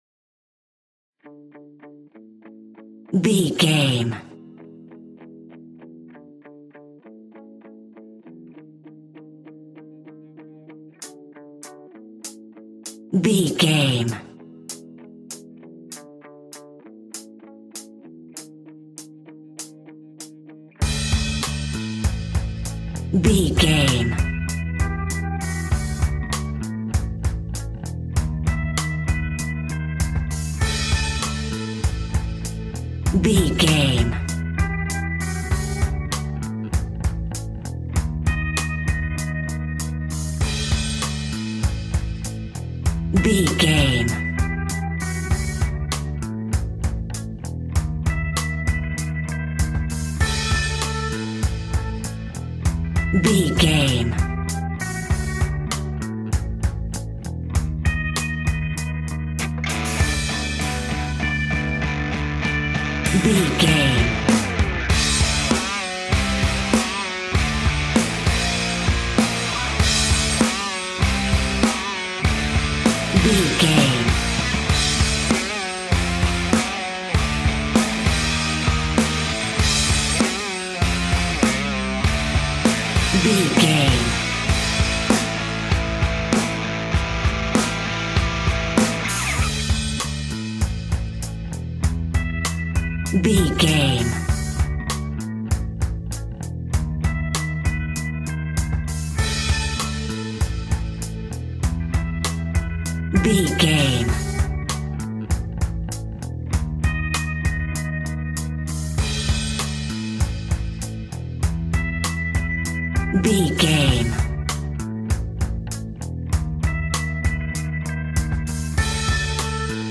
Epic / Action
Fast paced
Mixolydian
hard rock
heavy metal
dirty rock
rock instrumentals
Heavy Metal Guitars
Metal Drums
Heavy Bass Guitars